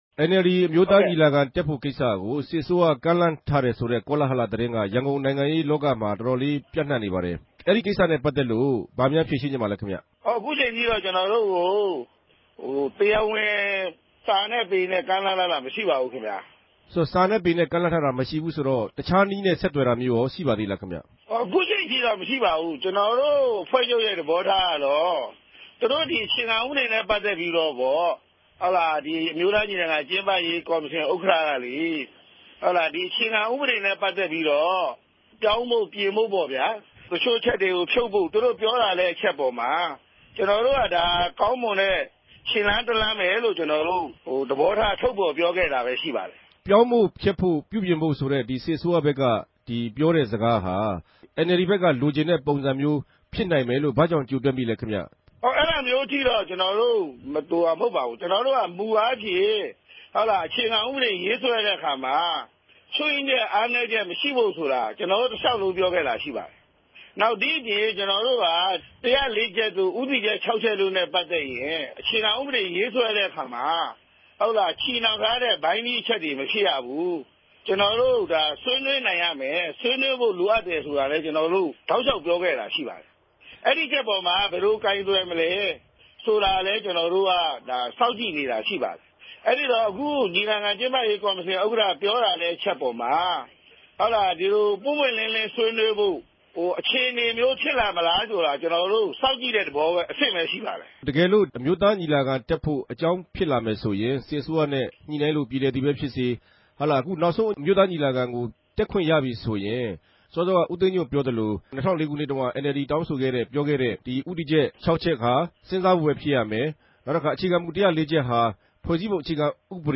ဗန်ကောက် RFA႟ုံးခြဲကနေ ဆက်သြယ် မေးူမန်းထားပၝတယ်။